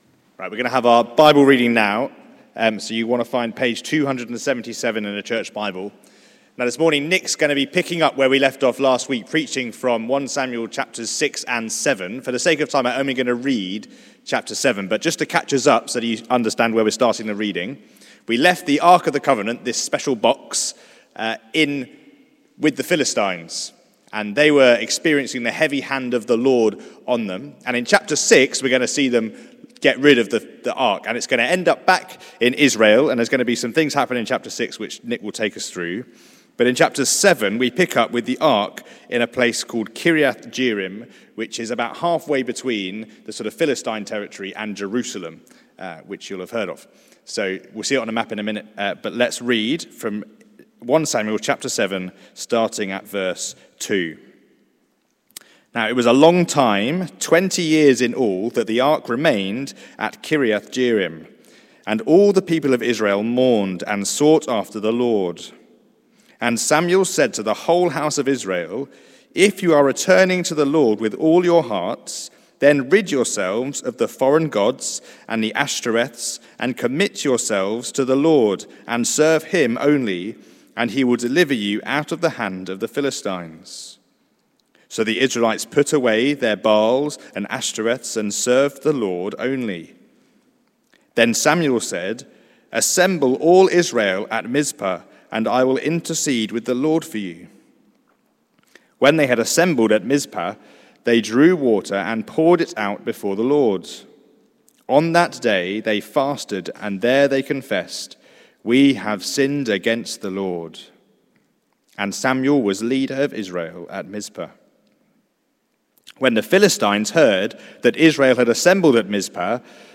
Theme: The Story of the Ark: Part 2 Sermon